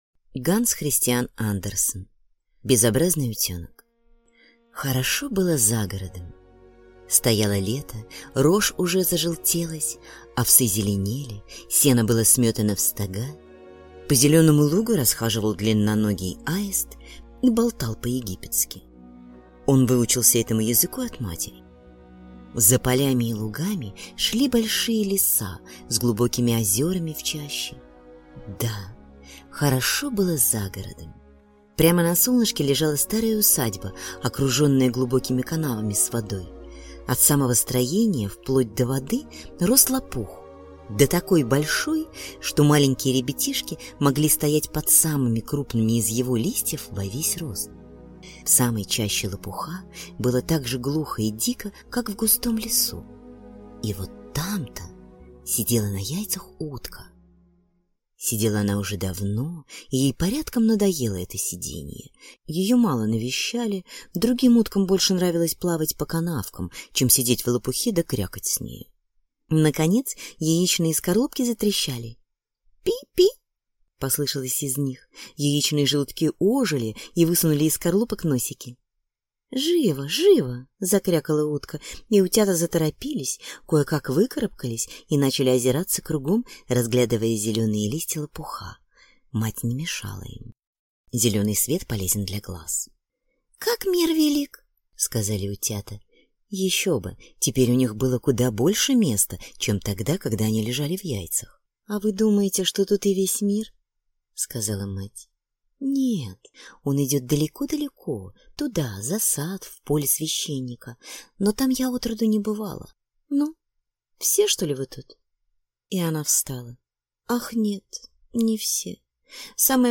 Аудиокнига Безобразный утёнок | Библиотека аудиокниг